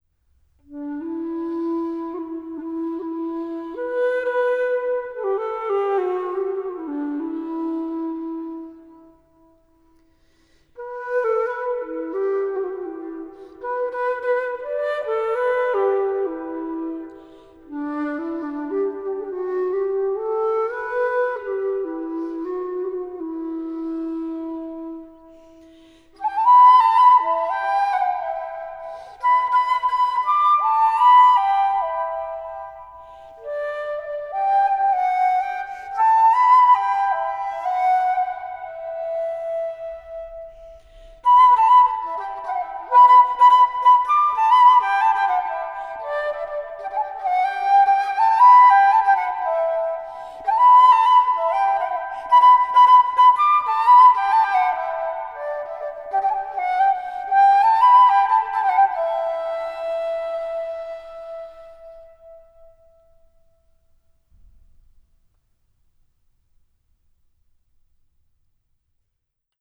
Traversflöte
Obgleich aus einem Stück Holz gedreht und ohne Klappen, unterscheidet sich ihr Funktionsprinzip nicht wesentlich von der heutigen Querflöte: Das Instrument war (meist) einteilig, wurde quer („zwerch“) gehalten und über ein Mundloch angeblasen.
musikinstrumentenmuseum_traversfloete.m4a